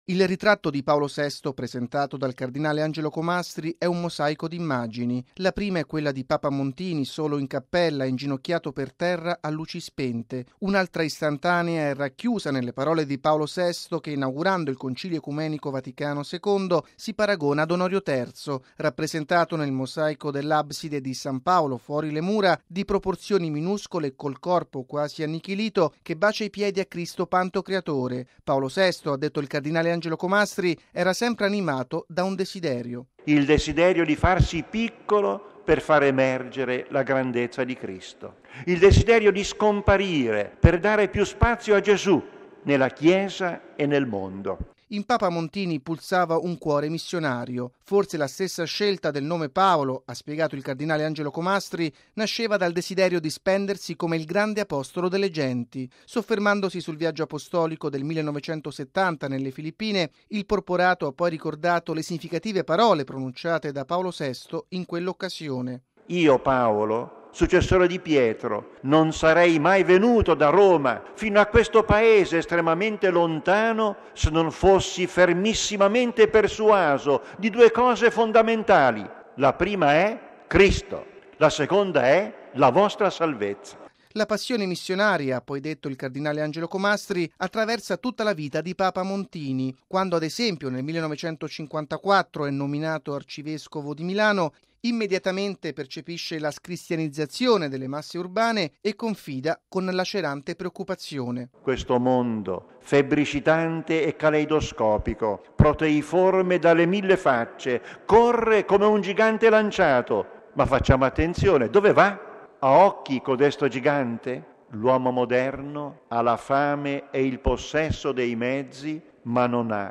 ◊   Il cardinale Angelo Comastri, vicario generale del Papa per lo Stato della Città del Vaticano, ha presieduto ieri pomeriggio, nella Basilica di San Pietro, la Santa Messa nel 31.mo anniversario della morte di Paolo VI.